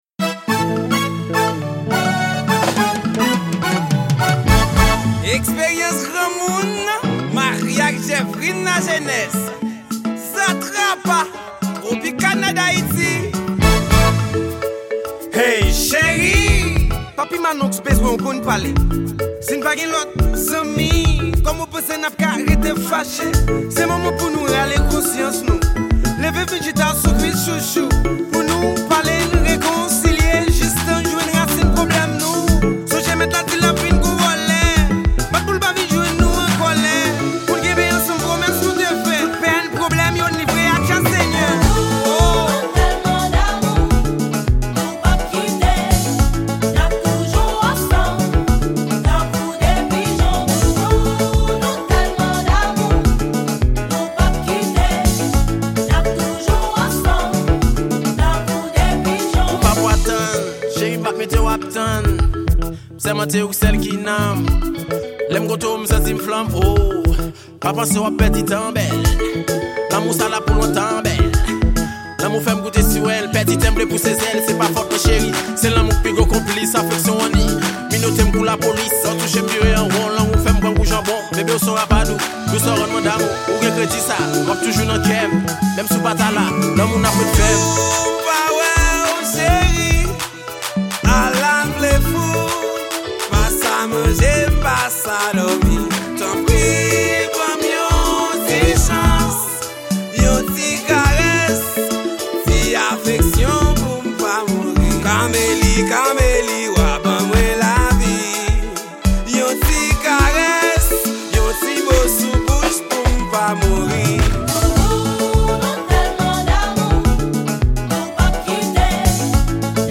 Genre: Alternative.